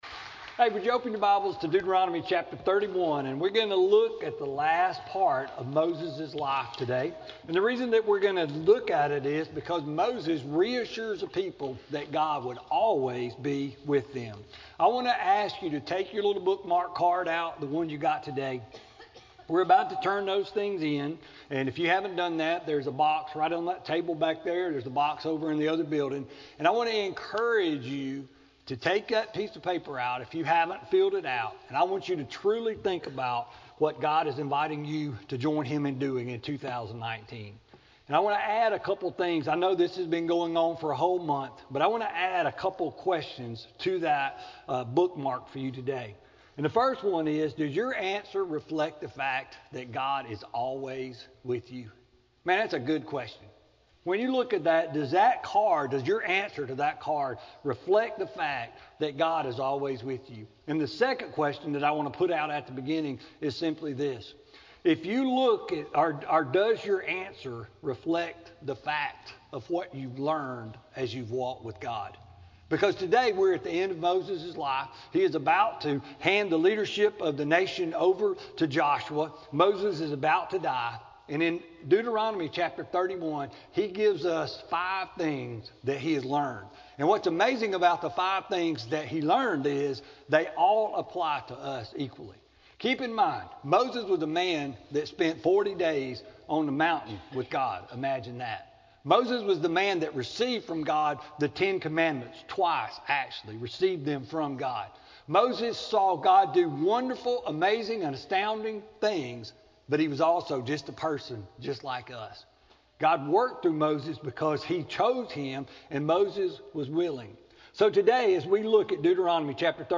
Sermon-1-27-19-CD.mp3